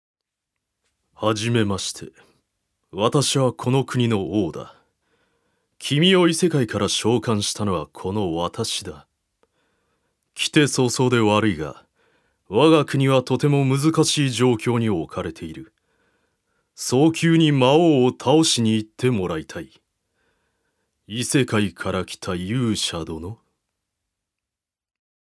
所属：男性タレント
音声サンプル
セリフ３